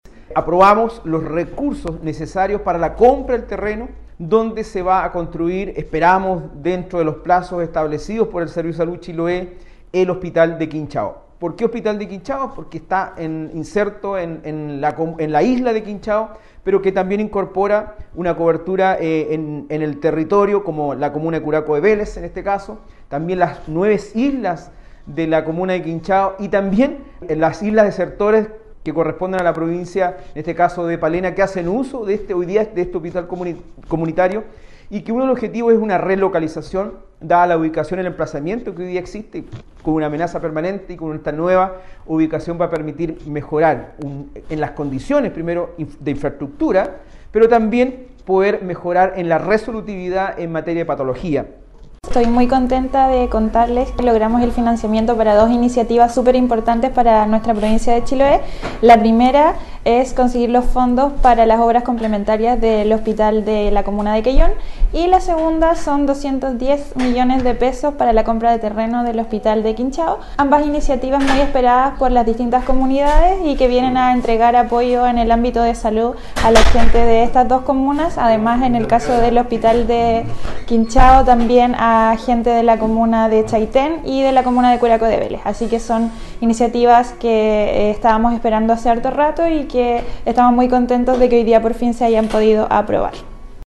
El Consejero Regional, Francisco Cárcamo recalcó que se trata de un centro de salud que tendrá una gran cobertura, incluyendo a las islas Desertores, pertenecientes a la provincia de Palena.
En tanto, la Consejera Regional, Daniela Méndez, manifestó que junto con la aprobación de recursos para la compra de terrenos del Hospital de Achao, también dispuso financiamiento para las obras complementarias del hospital de Quellón.